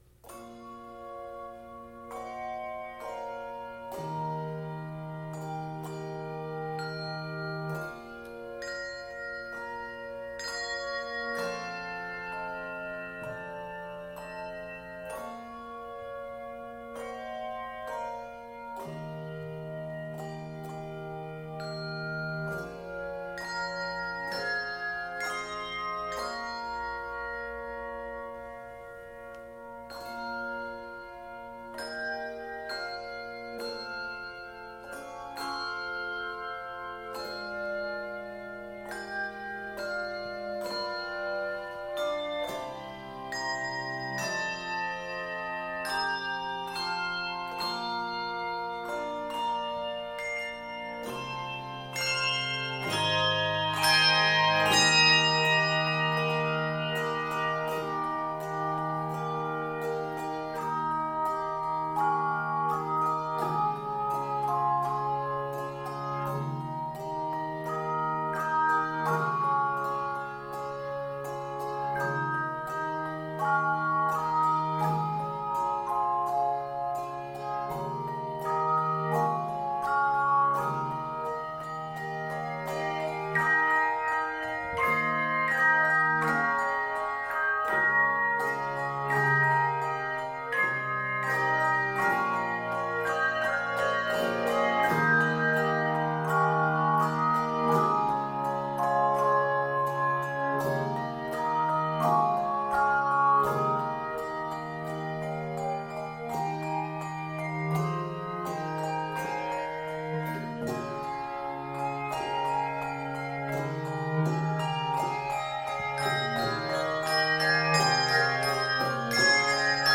A mournful minor verse